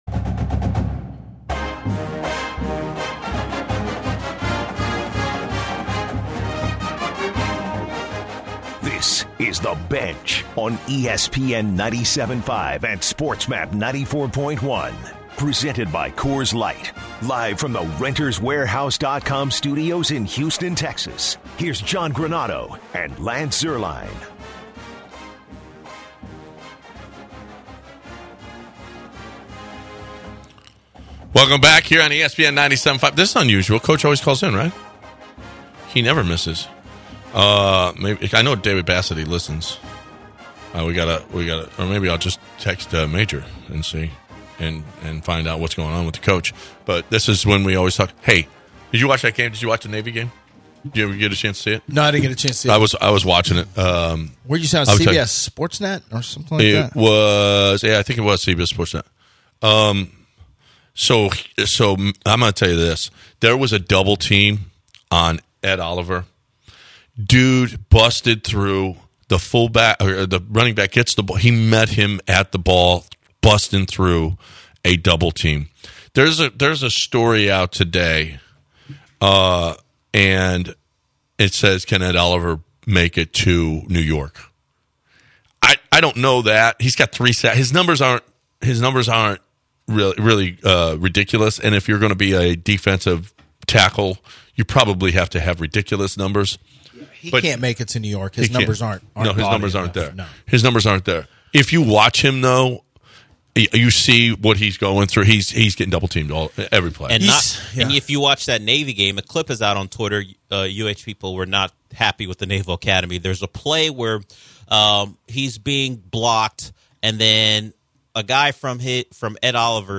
via phone